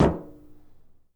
footsteps / metal
Added head bob & footstep SFX
metal1.wav